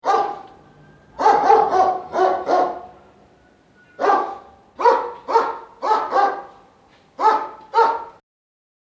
Animals
Dog(141K) -Chick(141K)